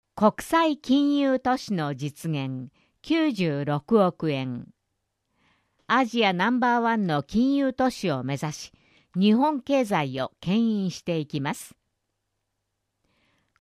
「広報東京都音声版」は、視覚に障害のある方を対象に「広報東京都」の記事を再編集し、音声にしたものです。